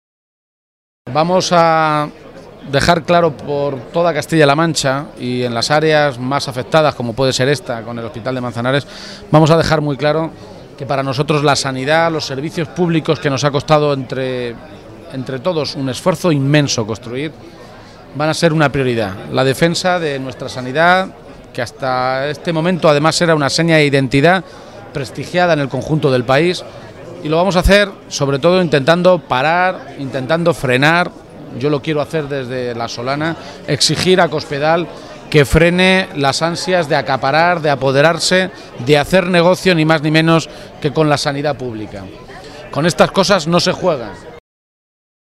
García-Page resaltó este compromiso en un encuentro comarcal celebrado en el municipio ciudadrealeño de La Solana, precisamente una comarca que si se cumplen las intenciones del Gobierno regional sufrirá las consecuencias de la privatización del hospital de referencia de Manzanares.